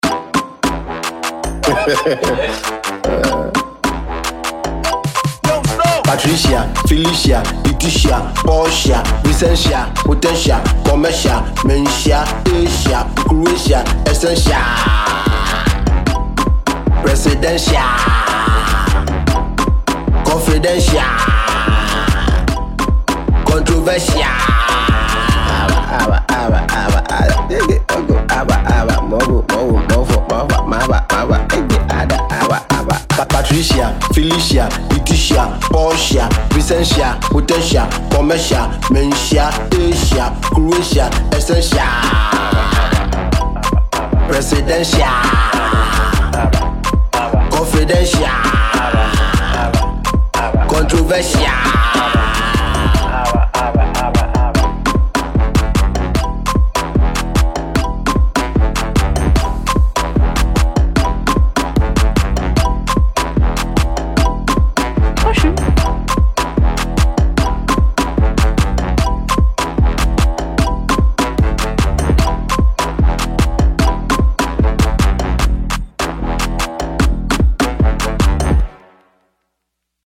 a danceable tune